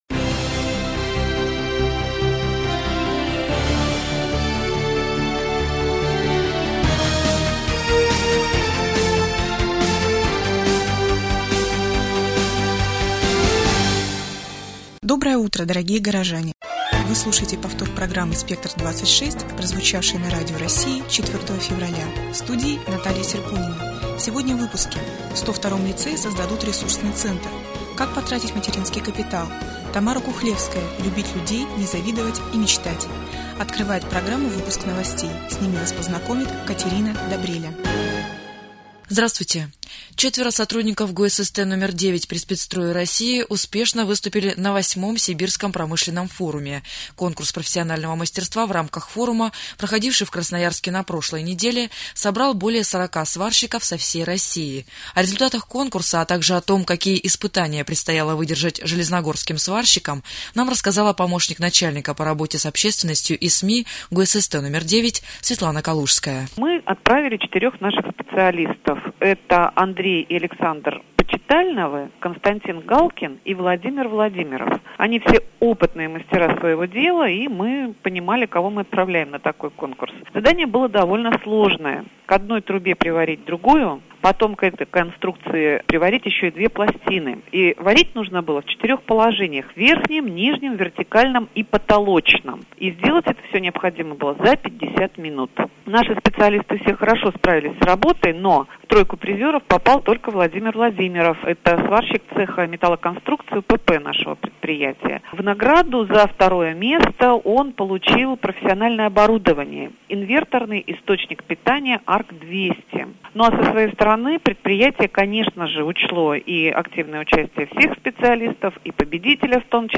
СЛУШАЙ РАДИОПРОГРАММУ ЗА 4 ФЕВРАЛЯ » Свежее телевидение - Железногорск